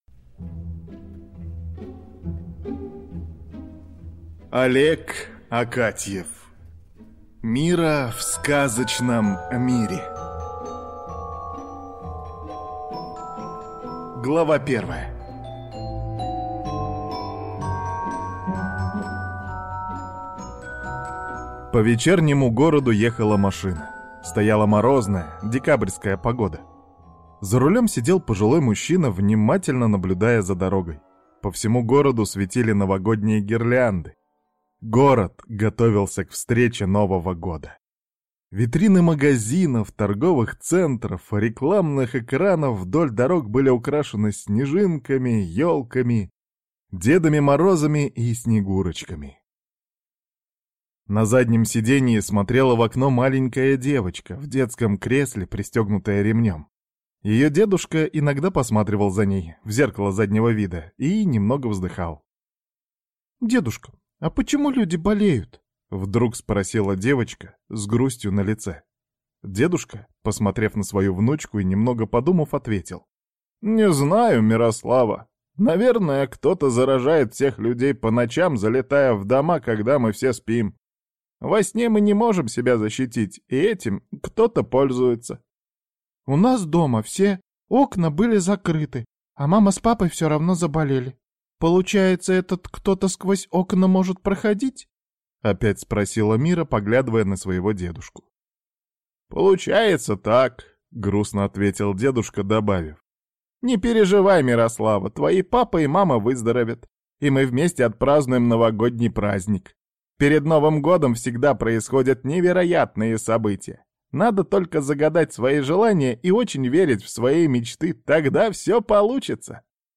Аудиокнига Мира в сказочном мире | Библиотека аудиокниг